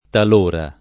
tal1ra] avv. — tronc. (antiq.): talor mi giova [tal1r mi J1va] (Dante); Talor risponde, et talor non fa motto [tal1r riSp1nde, e ttal1r non fa mm0tto] (Petrarca); Talor m’assido in solitaria parte [tal1r m aSS&do in Solit#rLa p#rte] (Leopardi)